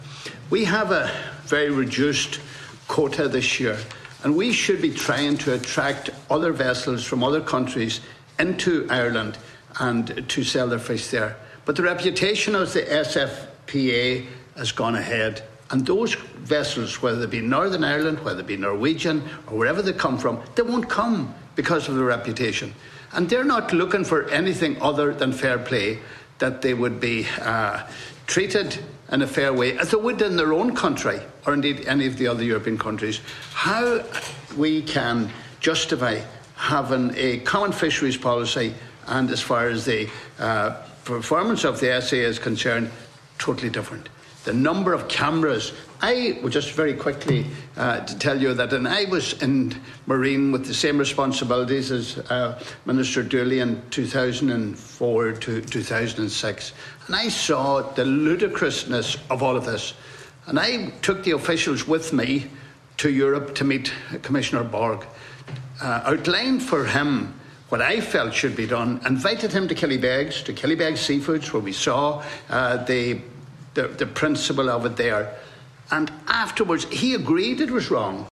Donegal Deputy Pat the Cope Gallagher says at a time where Ireland should be attracting more vessels due to quota cuts, they can’t due to this reputation: